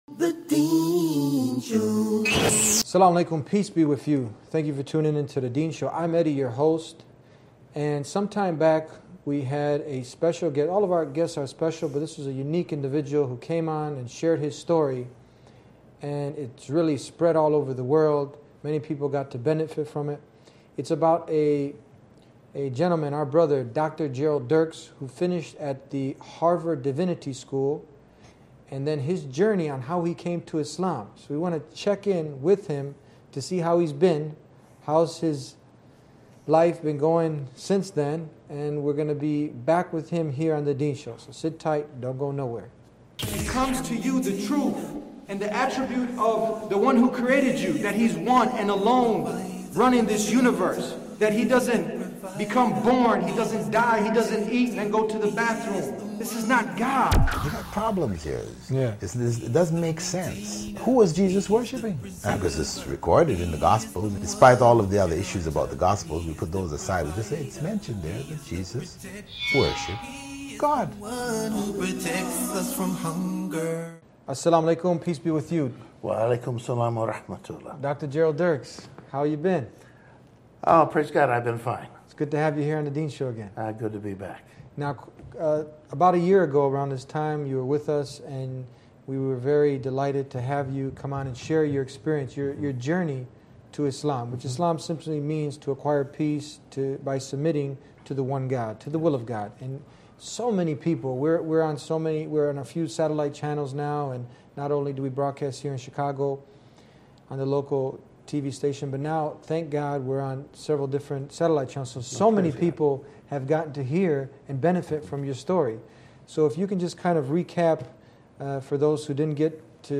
This weeks guest is a Scholar of Christianly and an authority on the Bible who saw that the Bible we have today has been changed and corrupted so it can’t be from God so he kept on looking and didn’t give up and found the truth in ISLAM .